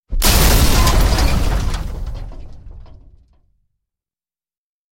Звуки взрыва танка
Звук вибуху танка: снаряд влучає у броню